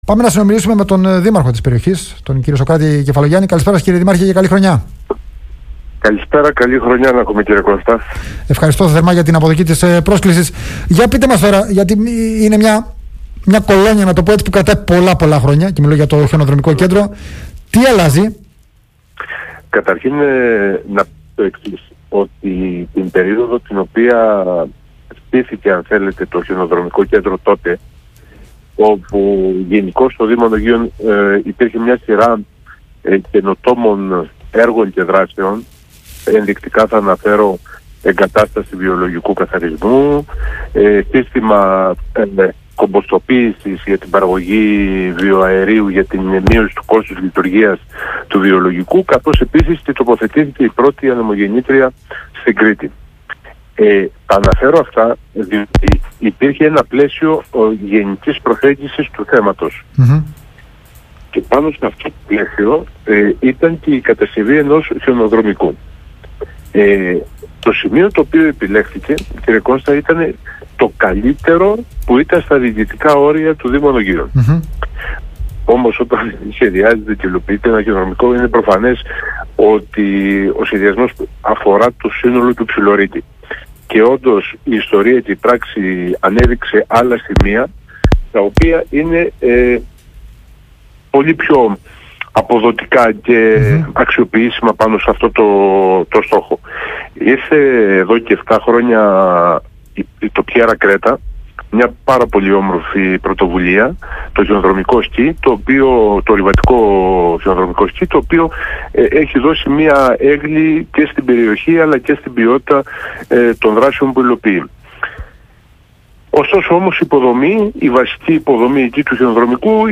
Σε κέντρο Δραστηριοτήτων χιονιού για μικρά παιδιά και οικογένειες θα μετατραπεί το άλλοτε χιονοδρομικό κέντρο των Ανωγείων , όπως επεσήμανε ο Δήμαρχος